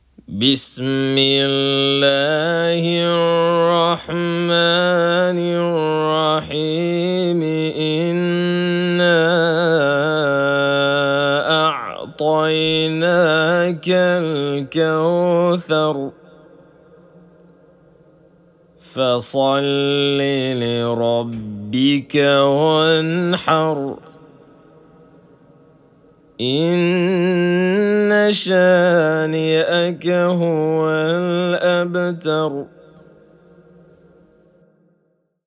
알 카우싸르 (무자와드 양식)